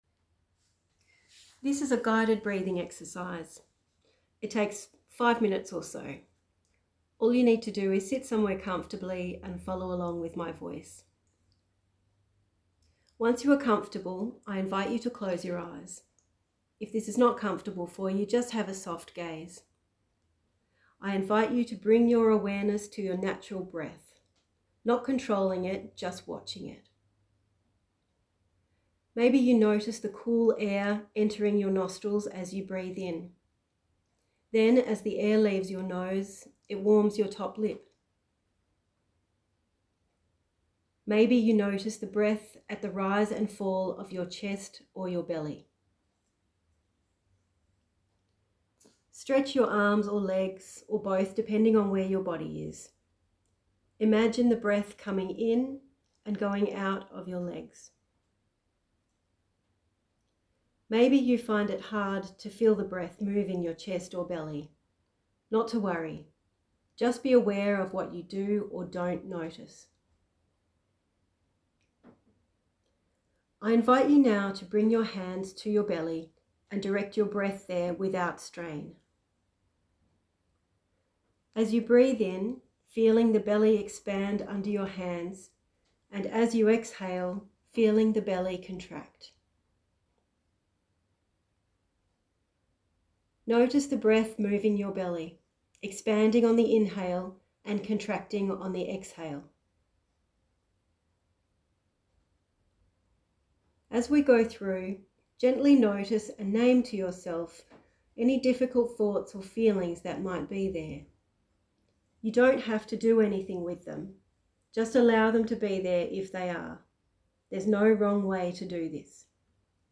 Guided Breathing Exercise in English – 5.41 MB